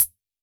RDM_Copicat_SR88-ClHat.wav